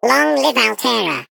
Sfx_tool_spypenguin_vo_selfdestruct_03.ogg